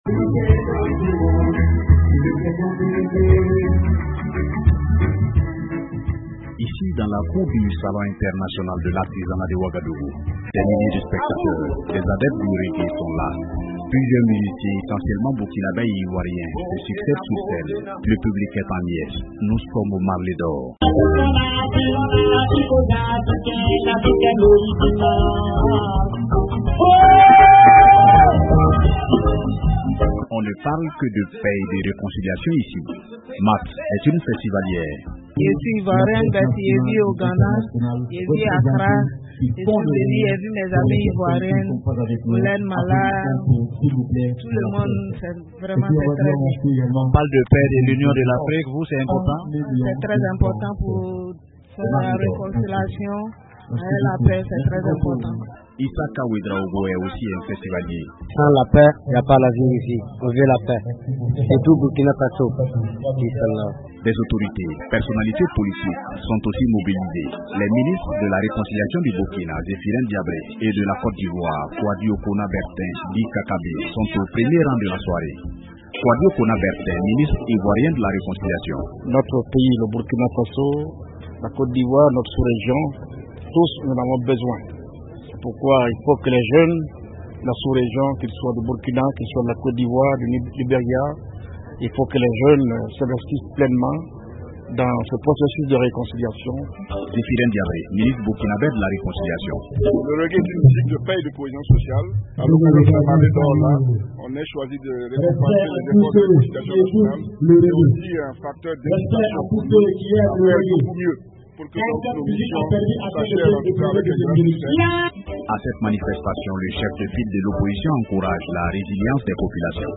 Dans la cour du Salon international de l’artisanat de Ouagadougou, des milliers de spectateurs, des adeptes du reggae se sont réunis.
Le public est en liesse aux Marley d’Or.